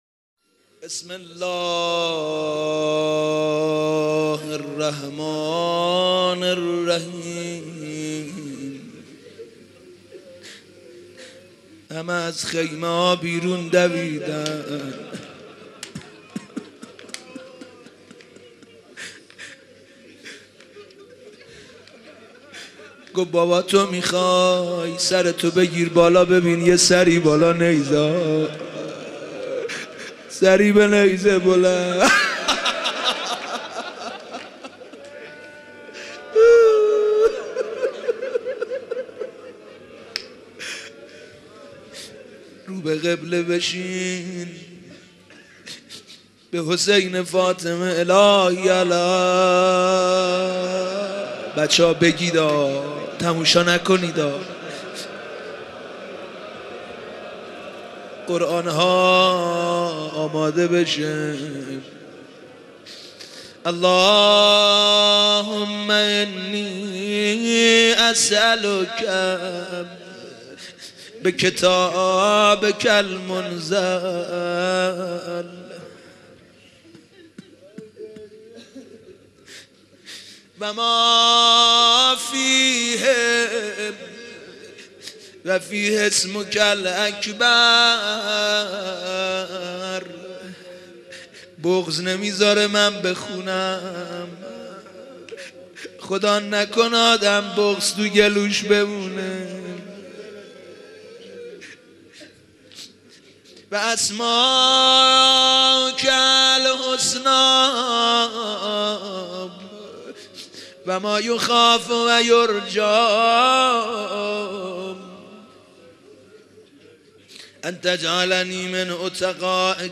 مراسم احیا